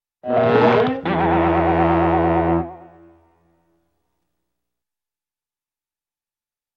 На этой странице собраны разнообразные звуки поворота головы – от легкого шелеста до выраженного хруста.
Звуковое сопровождение вращения головы в анимации